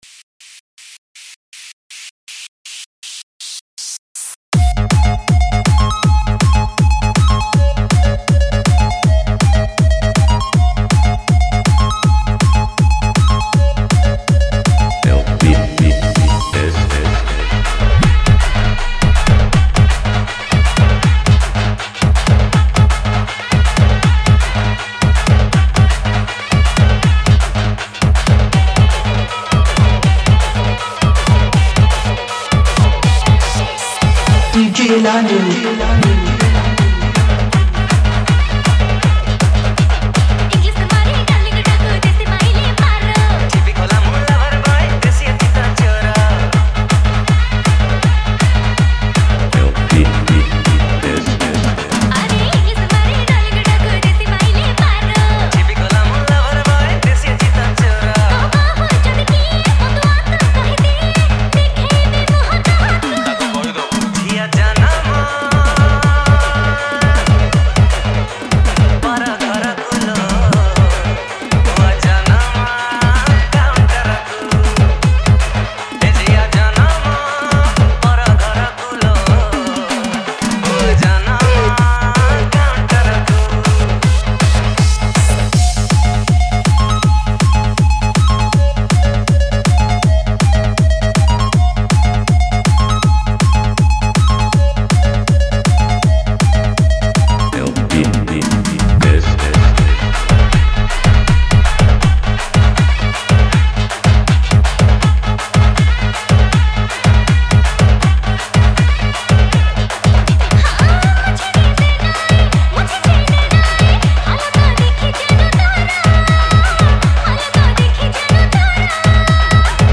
Odia Dance Remix